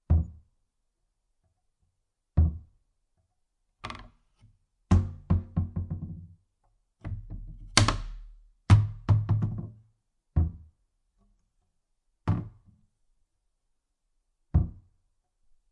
随机 " 门上的木质橱柜关闭时的颠簸
描述：门木柜关闭bumps.flac